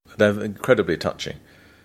/ˈtʌʧɪŋ/